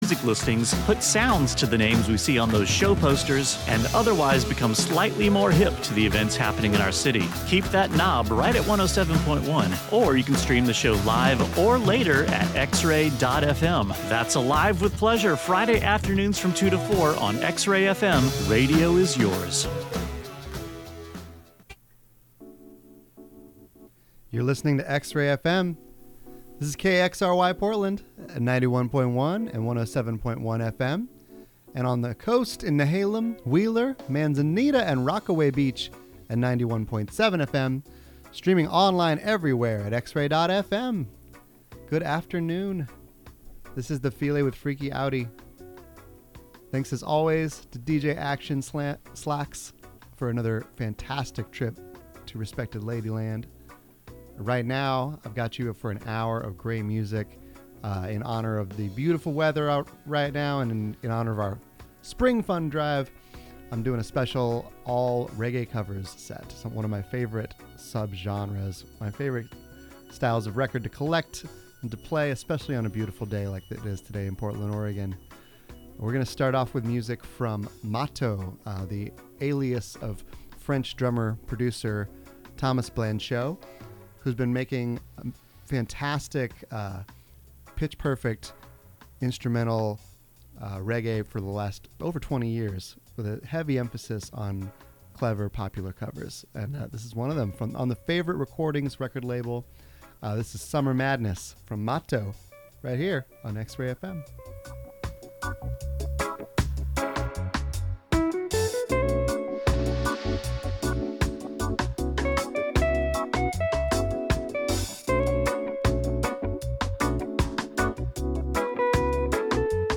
New and newly unearthed jams from all over to put you in a dancing mood.